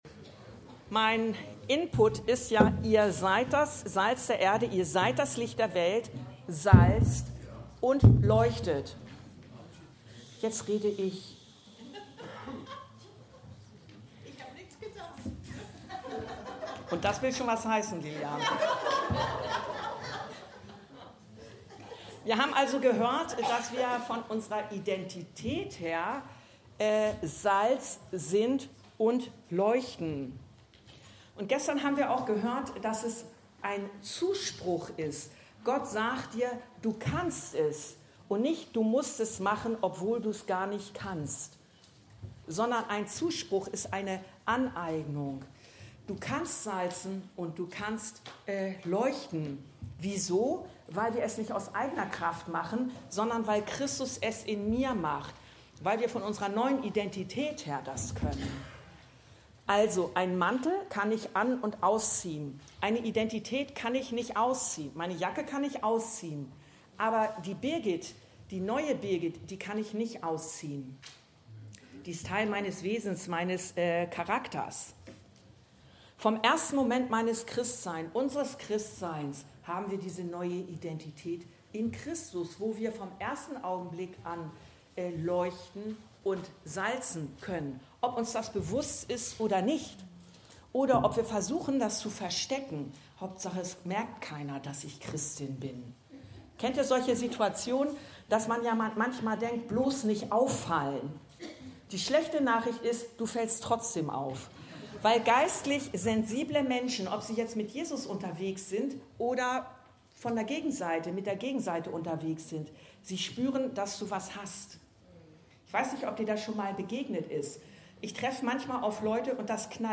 Salz und Licht- das Thema unserer Gemeindefreizeit 2023.
Predigten und Lehre aus der Anskar-Kirche Hamburg-Mitte